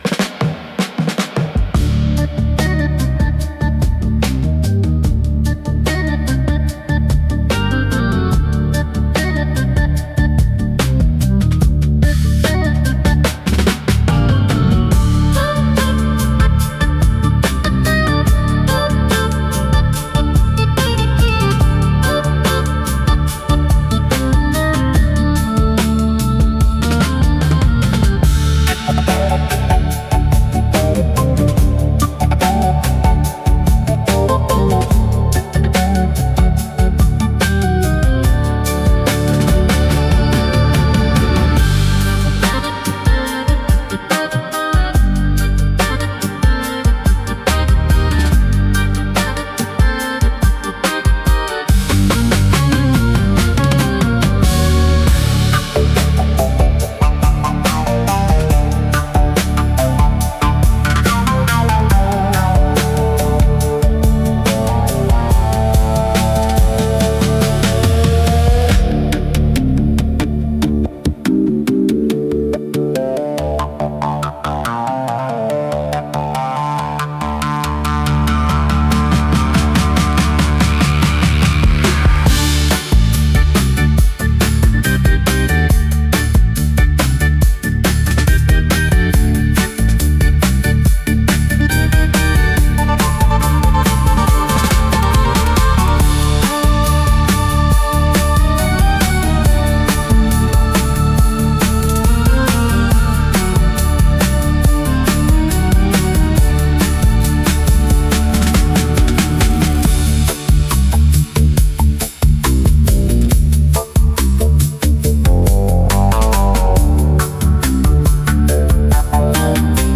イメージ：インスト,サイケデリック・ロック,オルガン
インストゥルメンタル（Instrumental）